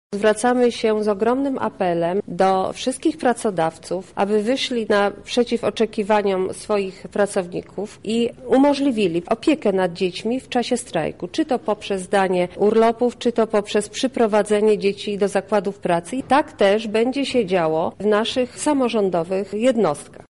-mówi Marta Wcisło, radna Miasta Lublin.